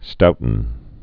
(stoutn)